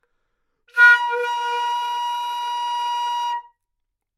长笛单音（吹得不好） " 长笛 Asharp4 糟糕的攻击
Tag: 好声音 单注 多样本 Asharp4 纽曼-U87